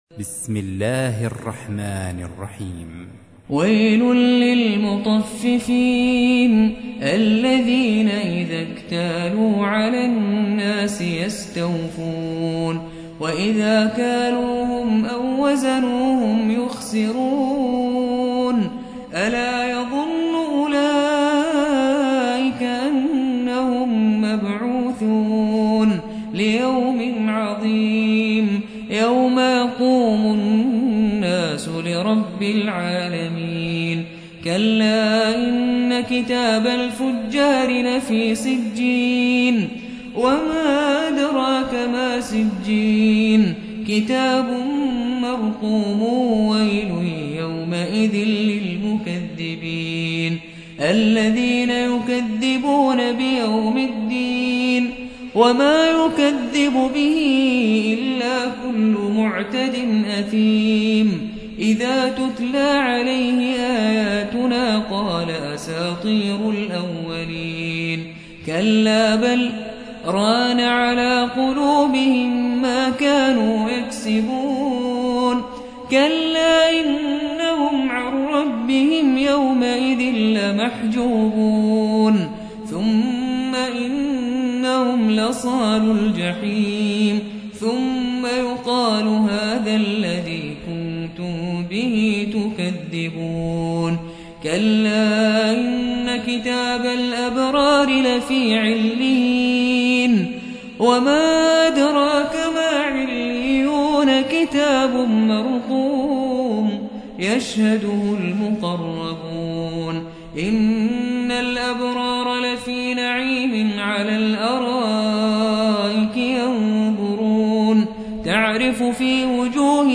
83. سورة المطففين / القارئ